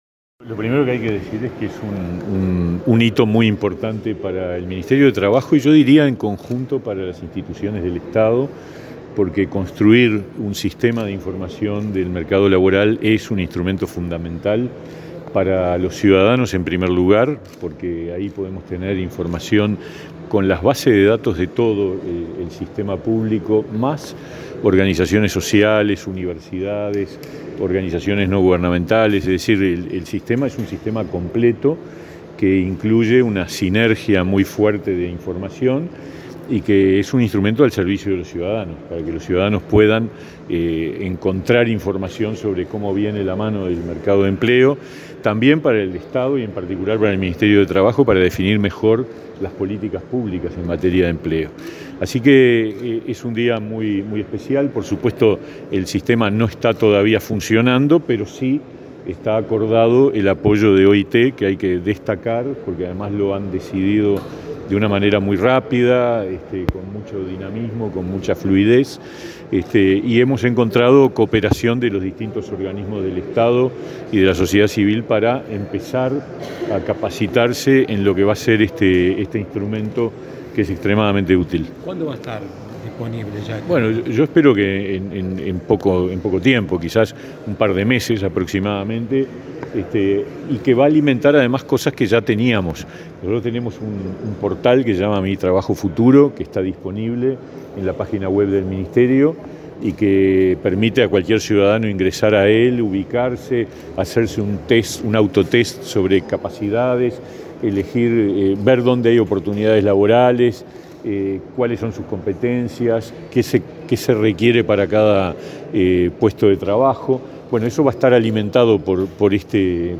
Declaraciones a la prensa del ministro de Trabajo, Pablo Mieres
Declaraciones a la prensa del ministro de Trabajo, Pablo Mieres 25/05/2022 Compartir Facebook X Copiar enlace WhatsApp LinkedIn Este miércoles 25 en Torre Ejecutiva, el Ministerio de Trabajo y Seguridad Social (MTSS) y la Organización Internacional del Trabajo (OIT) firmaron un memorando para la implementación del Sistema de Información del Mercado Laboral (Simel) en Uruguay. Luego, el ministro Pablo Mieres dialogó con la prensa.